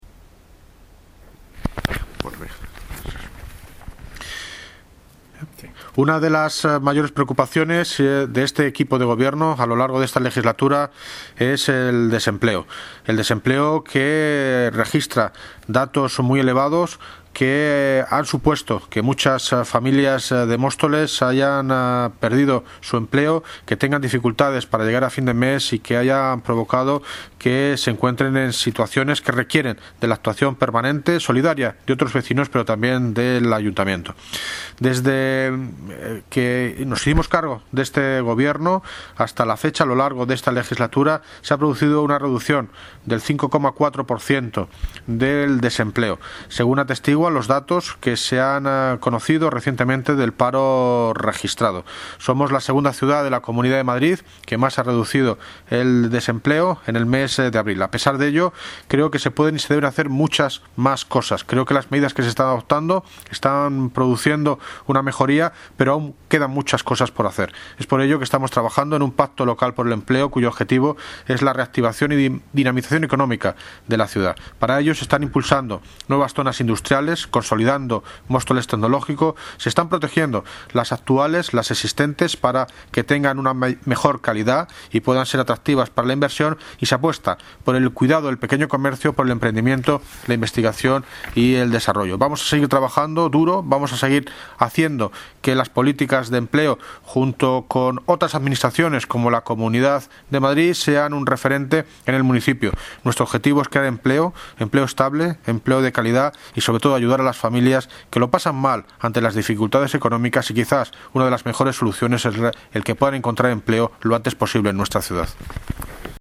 Audio - David Lucas (Alcalde de Móstoles) Sobre el paro en Móstoles